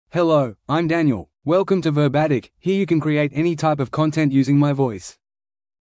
Daniel — Male English (Australia) AI Voice | TTS, Voice Cloning & Video | Verbatik AI
MaleEnglish (Australia)
Daniel is a male AI voice for English (Australia).
Voice sample
Daniel delivers clear pronunciation with authentic Australia English intonation, making your content sound professionally produced.